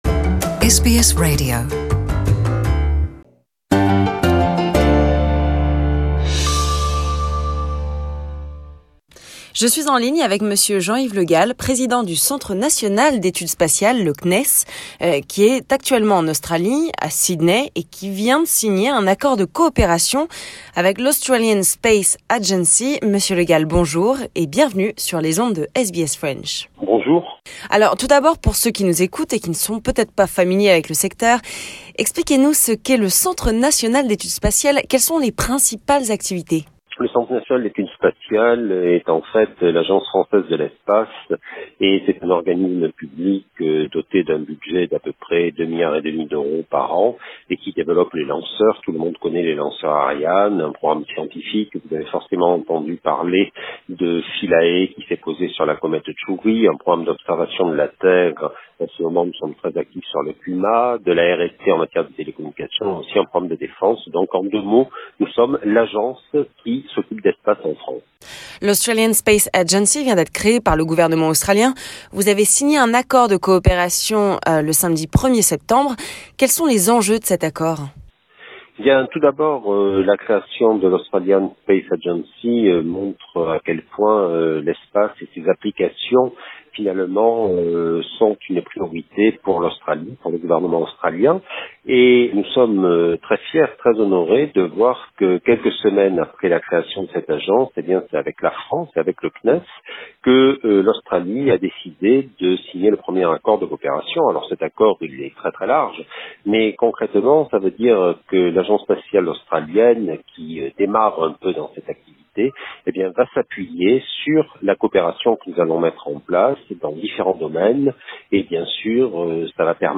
SBS French talked to Jean-Yves LeGall, President of the National Center for Space Studies (CNES) who has just signed a cooperation agreement with the Australian Space Agency, strengthening Franco-Australian relations.